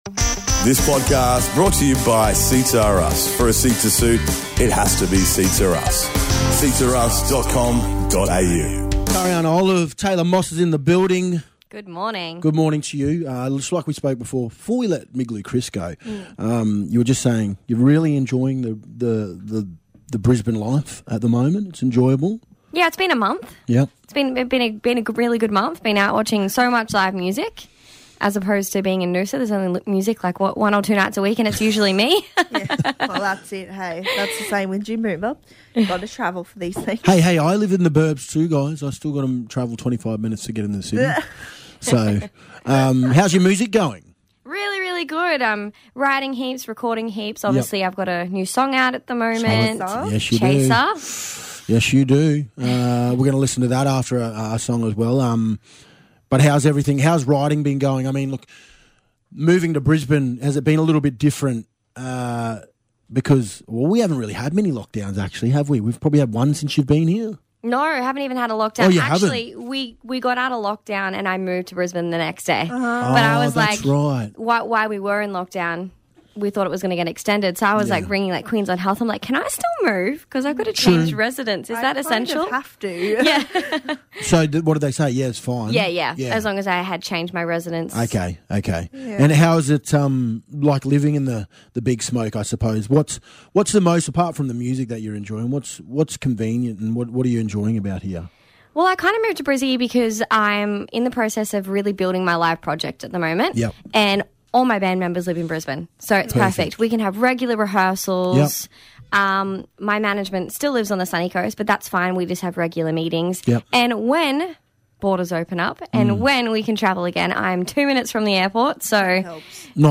Friday Live featuring her new single